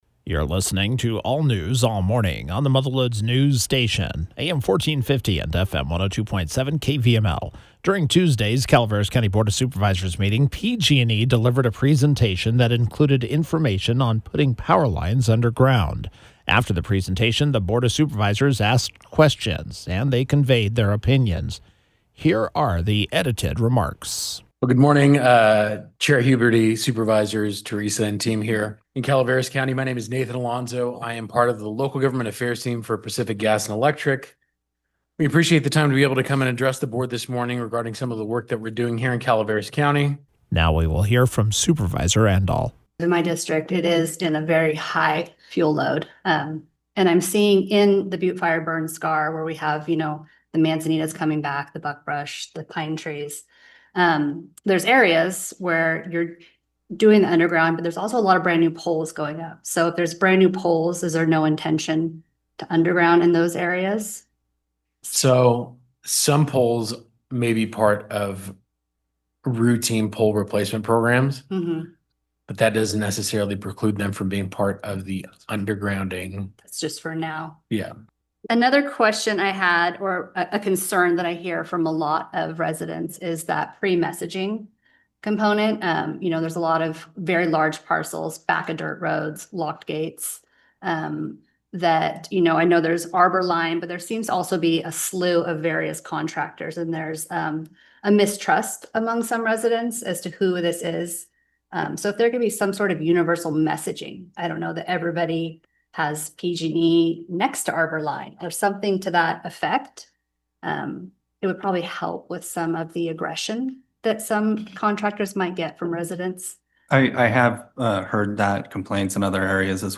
The edited discussion was featured as KVML’s “Newsmaker of the Day”.